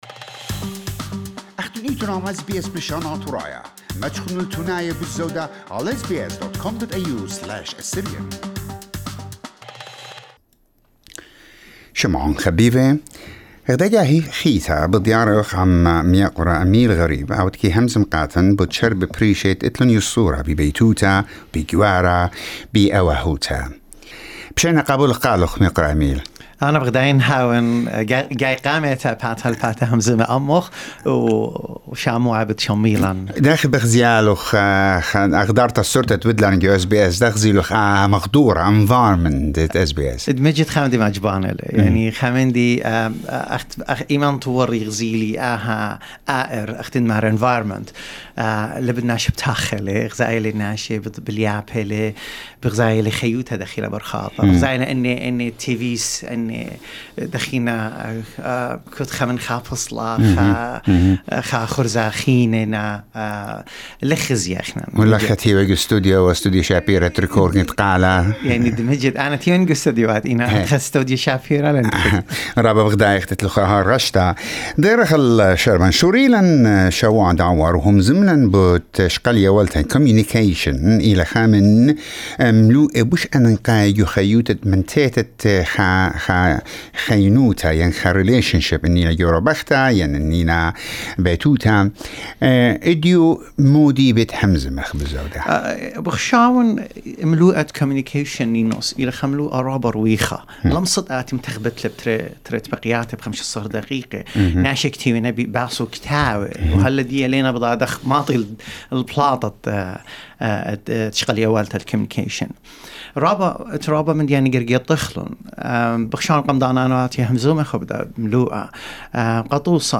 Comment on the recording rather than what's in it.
at SBS studio